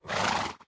minecraft / sounds / mob / horse / idle2.ogg